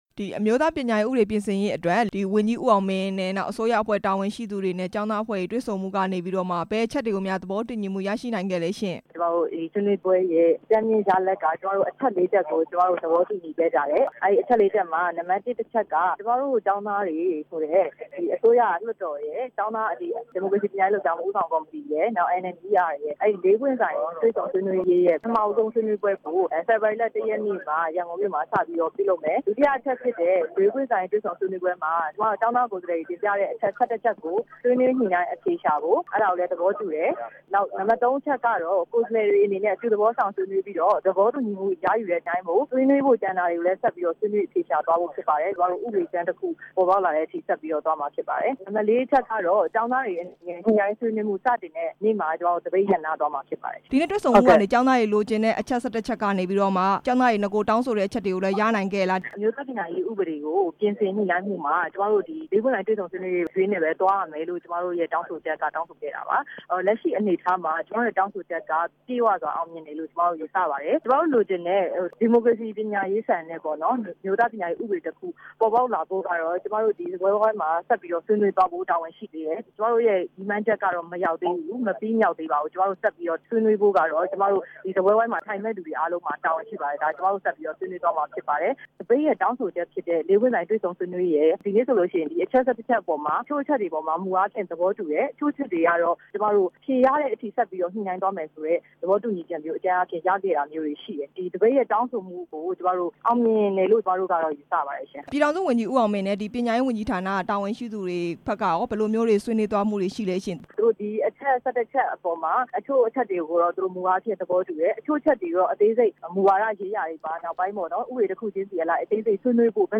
အစိုးရနဲ့ သပိတ်မှောက်ကျောင်းသားတွေ ဆွေးနွေးပွဲ မေးမြန်းချက်